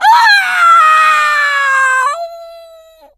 diva_die_vo_05.ogg